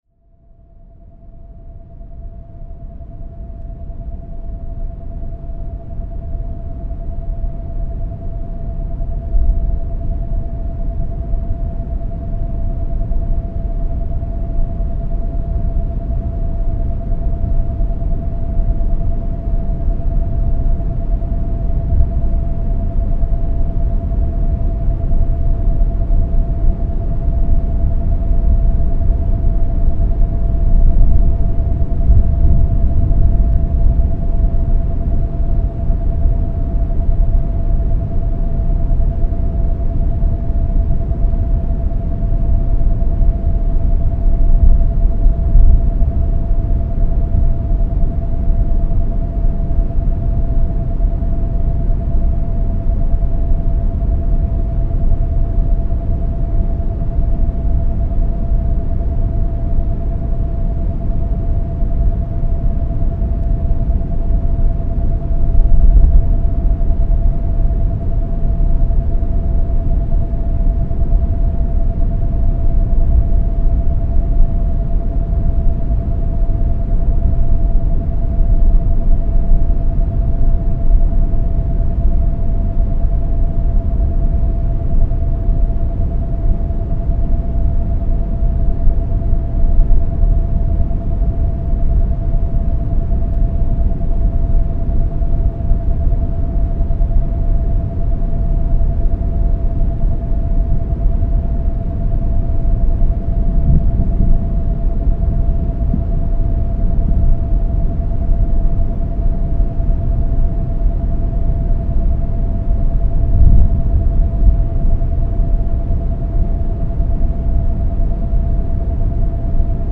Natural energy boost with frequencies.